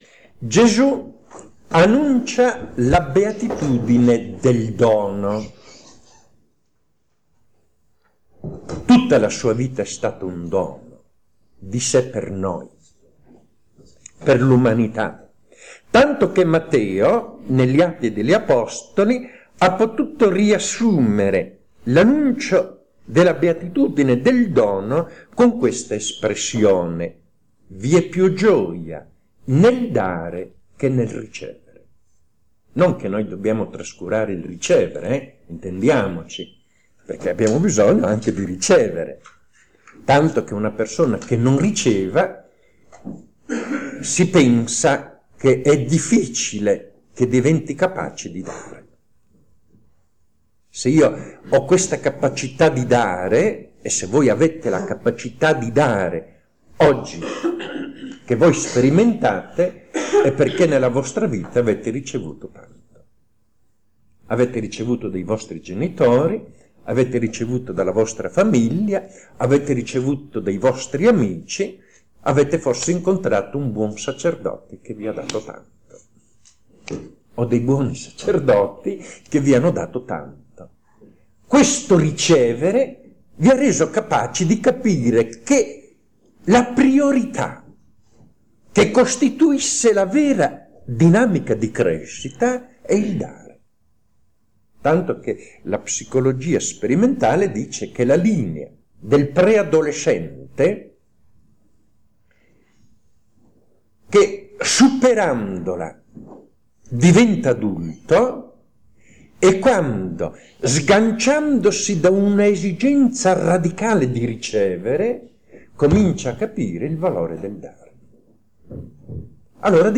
La quarta meditazione annuale (Gesù annuncia la beatitudine del dono), nella Casa di spiritualità Villa Annunciata (Località Casaglia 20045 – Besana in Brianza – MI)